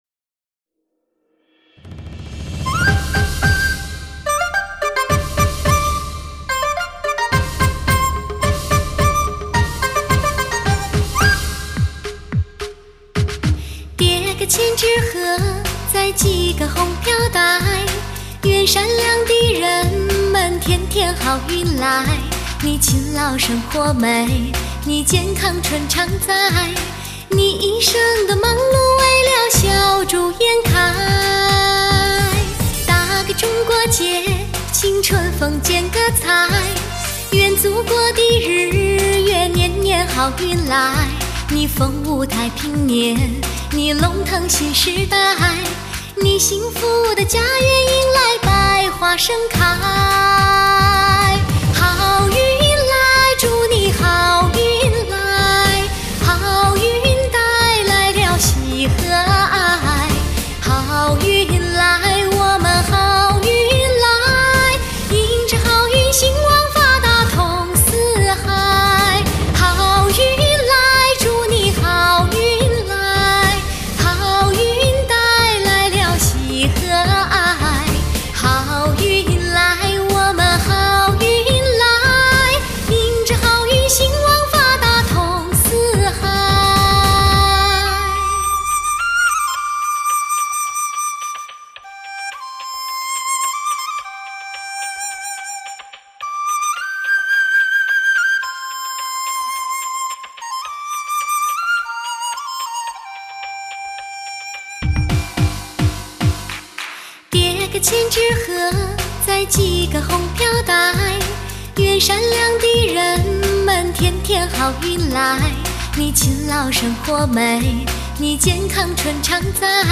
钟爱至极的民歌发烧天碟，民族与美声的完美结合，宛若牡丹香四溢，动听之处动心弦。
特别实现在汽车音响中的360环绕声技术；三维环绕立体声场，听觉宽广的“皇帝位”，
高音响层次，高传真现场感，让你的座驾HI-FI起来，充分享受驾驭中的美妙感觉，